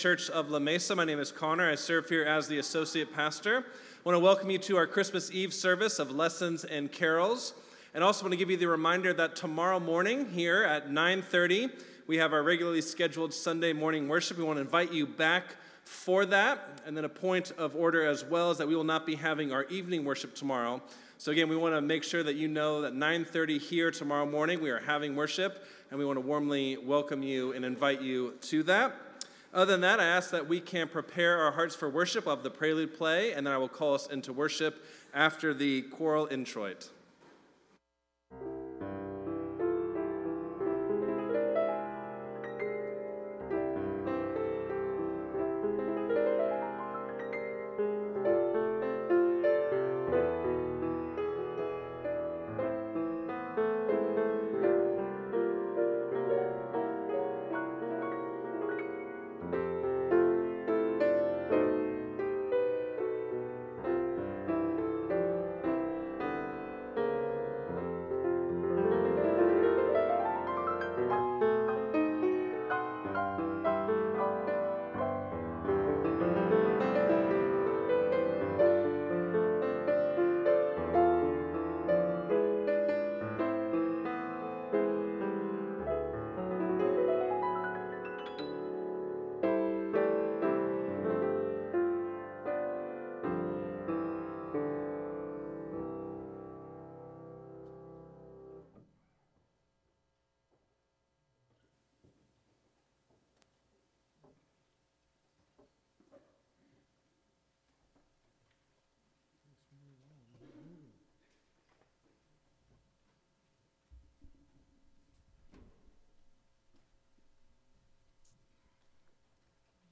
Christmas Eve Service
Lessons and Carols